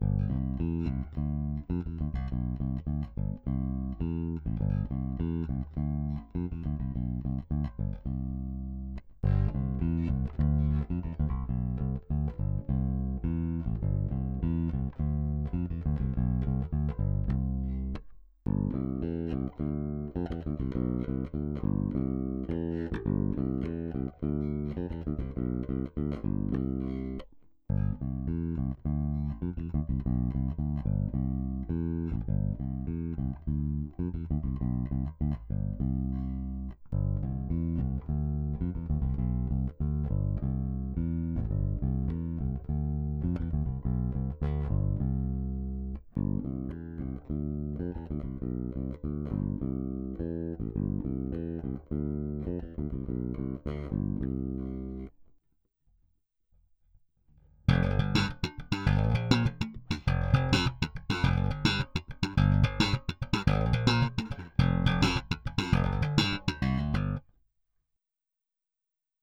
Das klingt doch schon mal sehr brauchbar!
Letzten Endes hat der PU etwas mehr Mitten/Tiefmitten und seidigere Höhen, als ein 60s gewickelter.
Die Nordstrand sind aber wirklich leiser ...